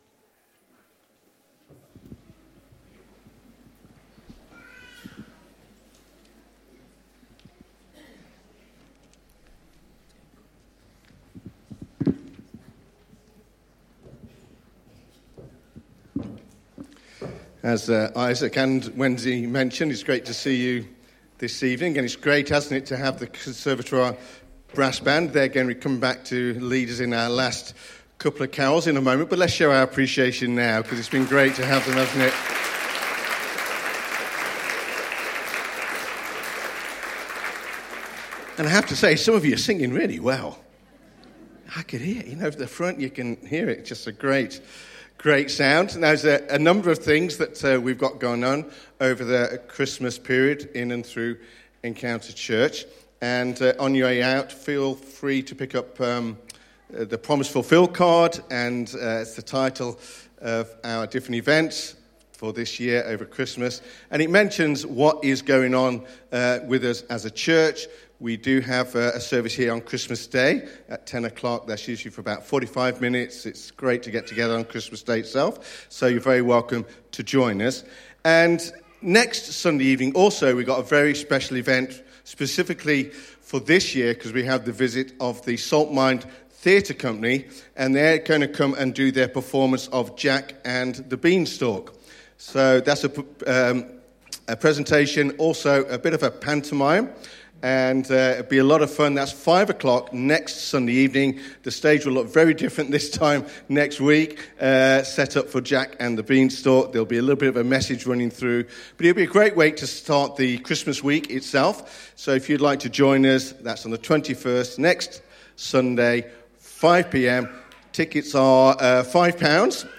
(Carol Service) Promised Names of Jesus